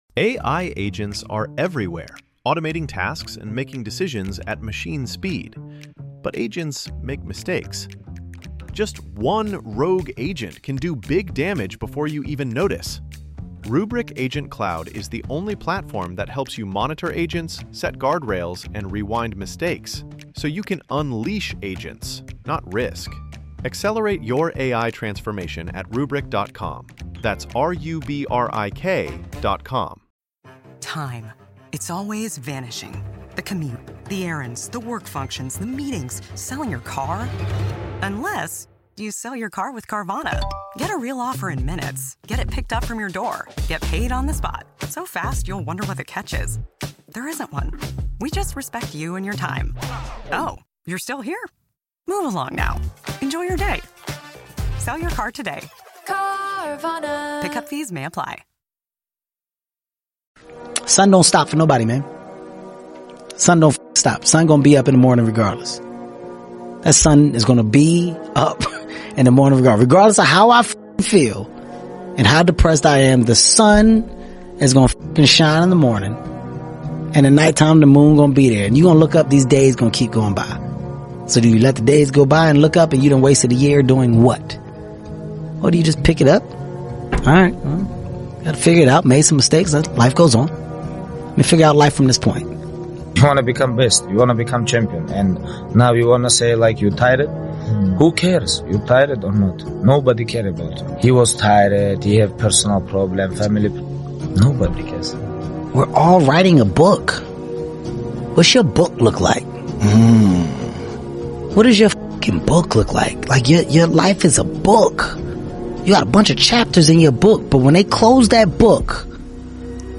This motivational speech is designed to inspire you to push beyond your limits, whether in the gym, at work, or in life.